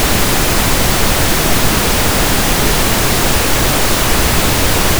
Pink Noise
pink_0dBFS_5s.wav